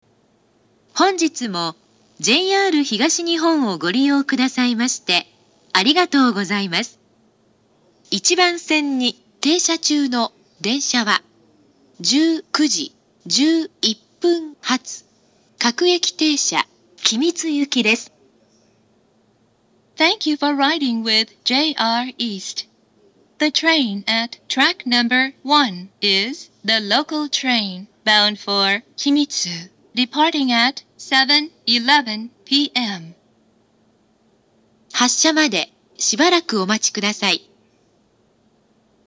２０１６年８月上旬頃には放送装置が更新され、発車メロディーの音質が向上しました。
１番線出発予告放送
発車メロディー（ＪＲＥ-ＩＫＳＴ-０0１-0２）
定時で発車できた場合でも１コーラスで切られてしまうこともあります。